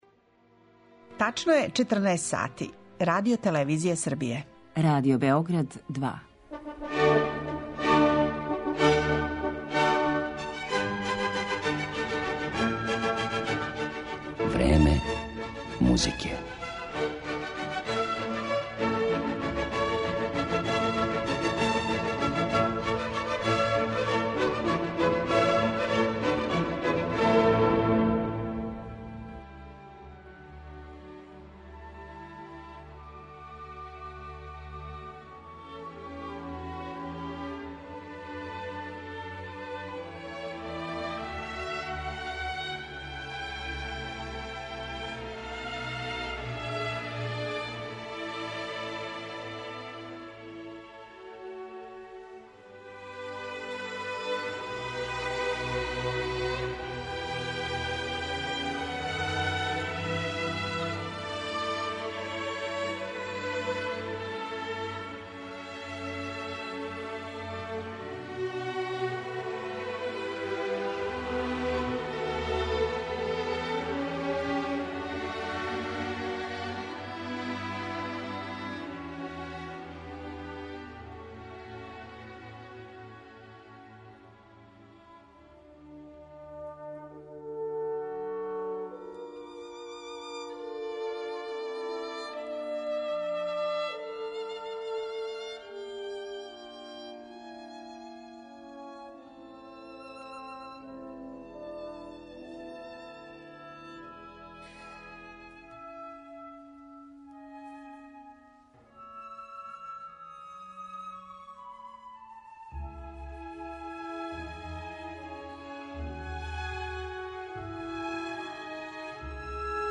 Ексклузивно ћемо емитовати одломке премијерно изведених композиција из пера Александре Вребалов, Ање Ђођревић, Зорана Ерића, Вука Куленовића и Драшка Аџића.